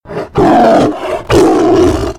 Tiger Growl Attack